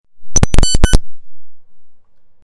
Electronic Glitch Sound Button - Free Download & Play